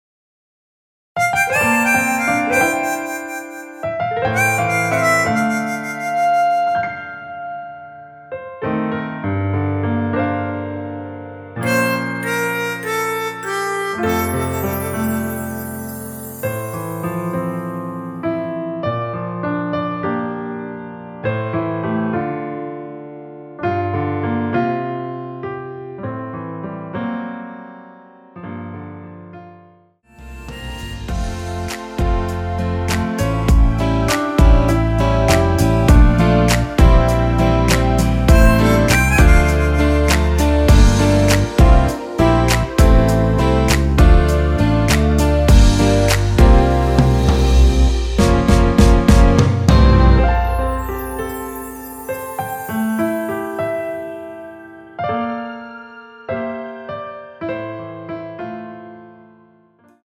원키에서(-2)내린(1절+후렴)MR입니다.
앞부분30초, 뒷부분30초씩 편집해서 올려 드리고 있습니다.
중간에 음이 끈어지고 다시 나오는 이유는